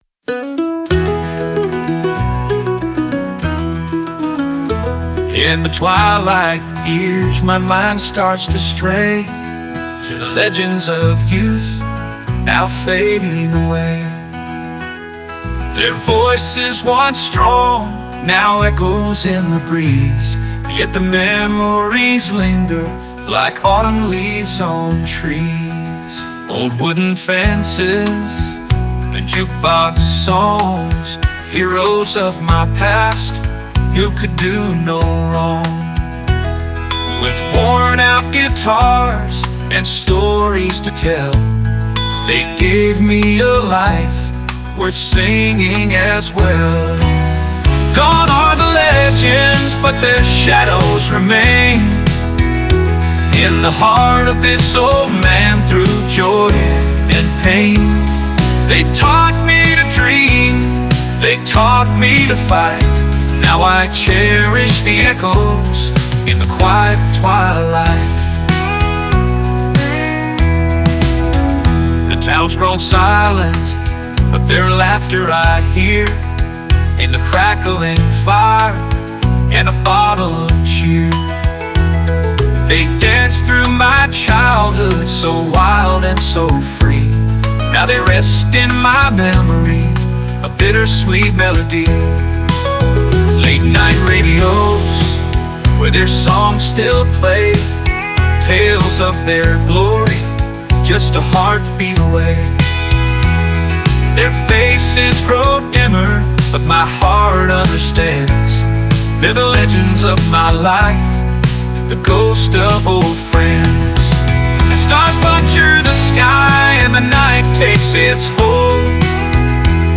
"Twilight Legends" (country)